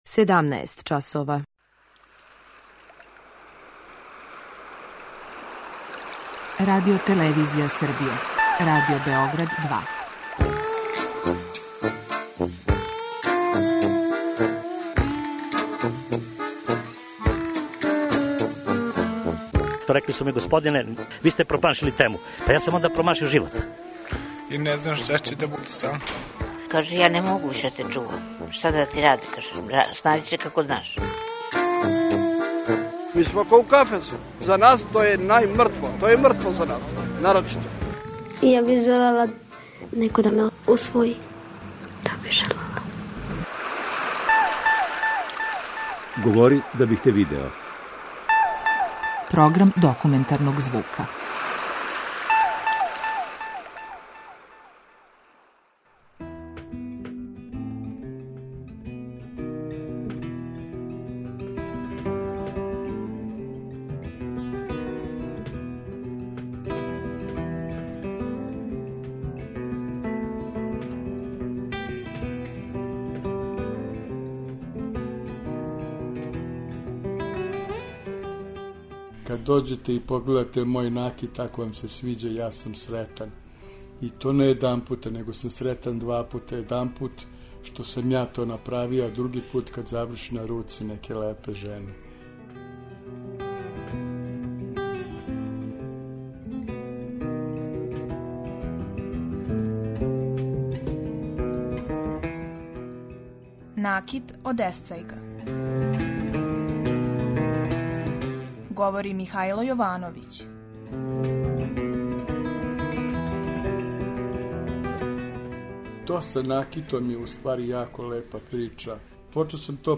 Документарни програм: Накит од есцајга